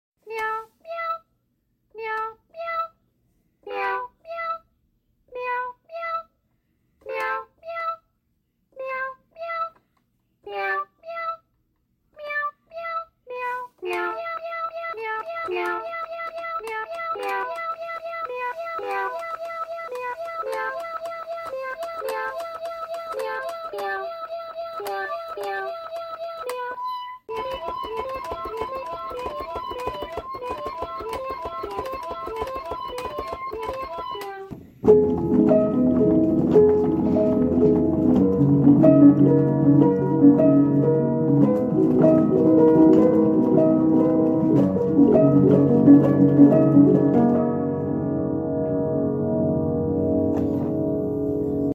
piano is flat ~60 cents so thats why it sounds like it pitches down